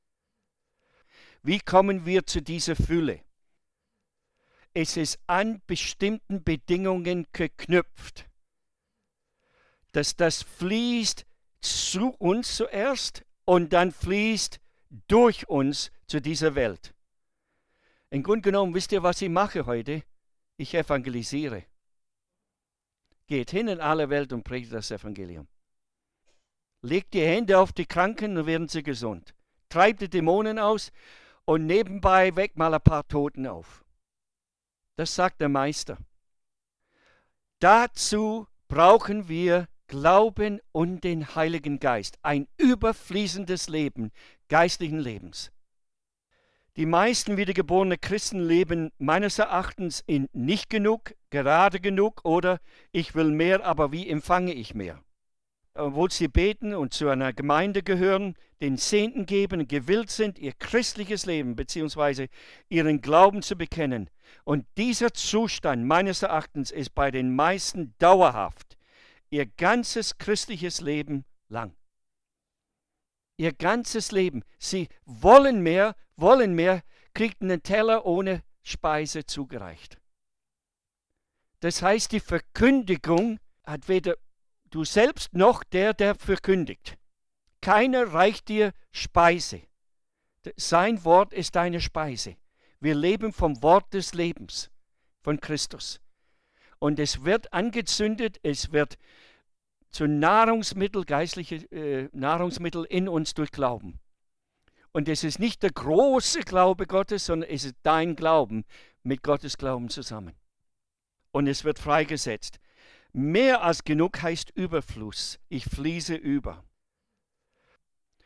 Der Vortrag zeigt den Weg heraus aus einem Leben im “gerade genug” hinein in ein “überfließendes” Leben.
Referent: